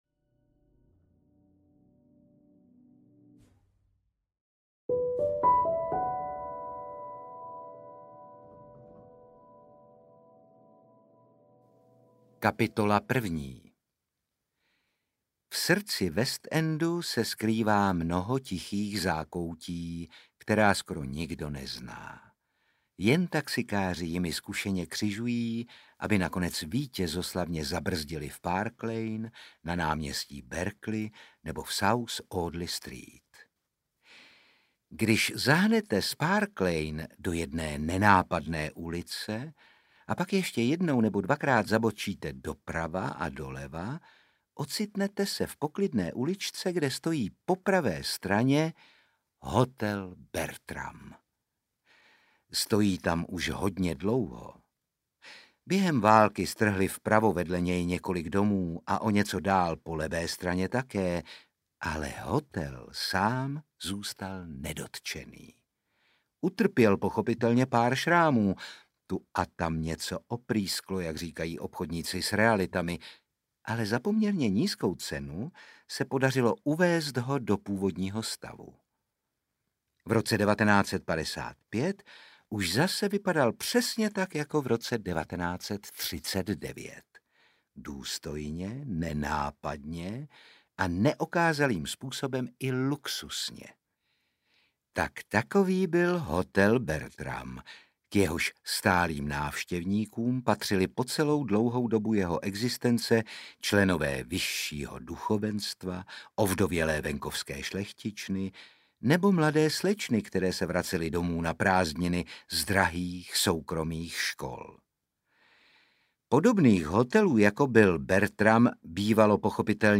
V hotelu Bertram audiokniha
Ukázka z knihy
• InterpretOtakar Brousek ml., Růžena Merunková